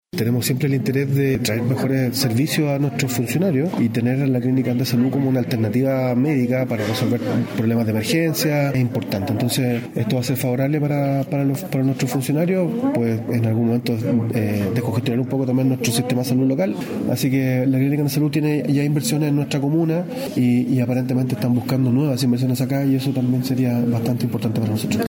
El alcalde Rubén Méndez valoró la alianza, destacando que permite contar con una alternativa médica de calidad para su personal: “Siempre hemos buscado ofrecer mejores servicios de salud a nuestros funcionarios, y contar con la Clínica Andes Salud como alternativa de urgencia es muy positivo. Refleja un compromiso con San Carlos y con la mejora de la cobertura sanitaria para todos”.